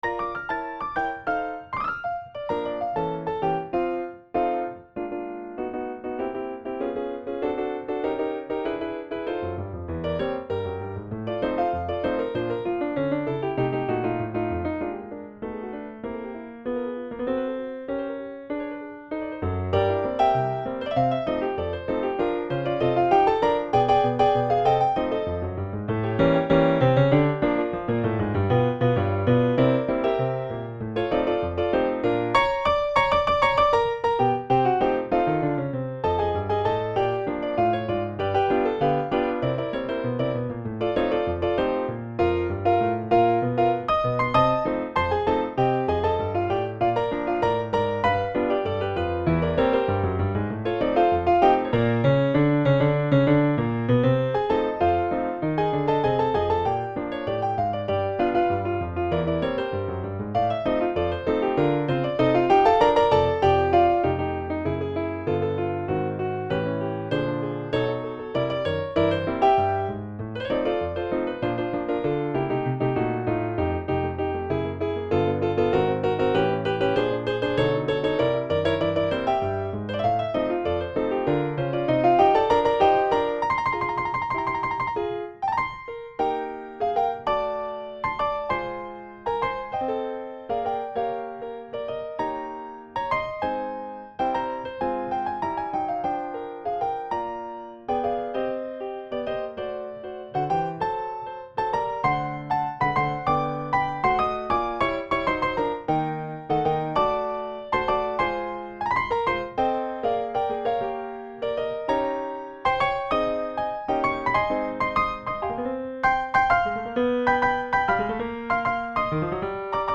Stride piano